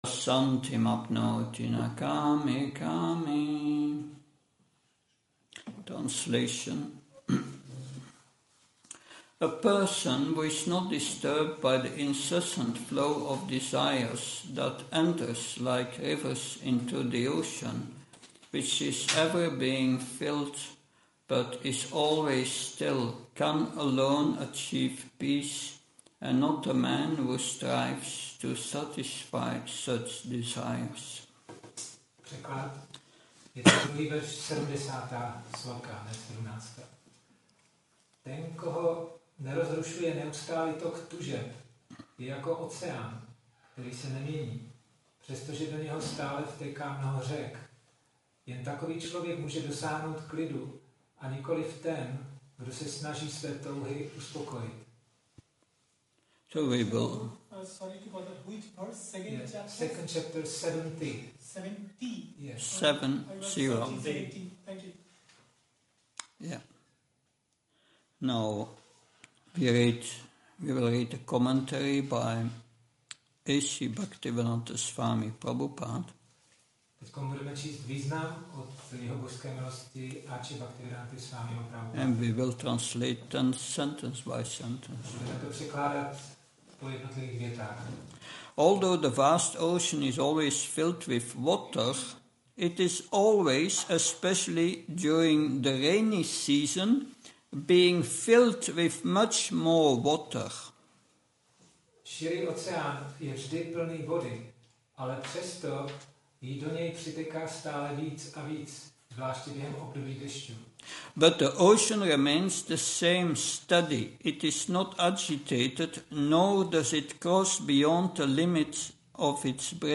Přednáška BG-2.7 – Jak udělat pokrok v duchovním vědomí – restaurace Góvinda